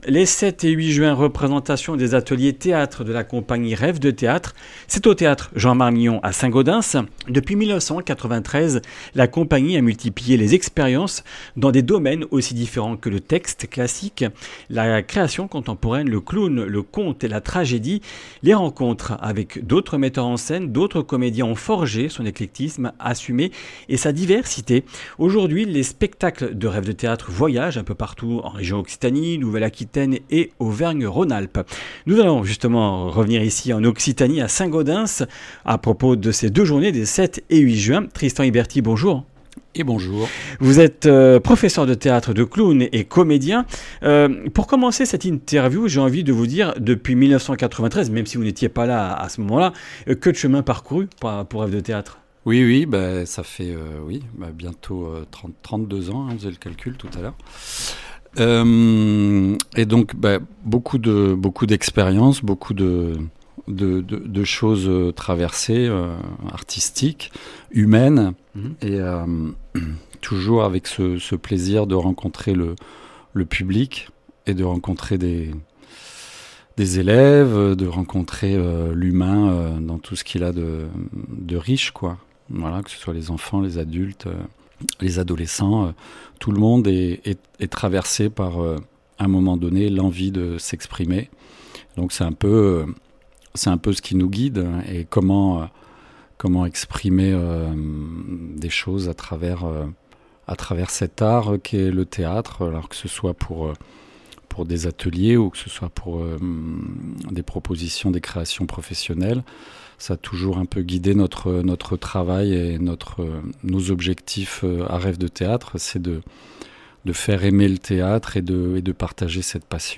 Comminges Interviews du 03 juin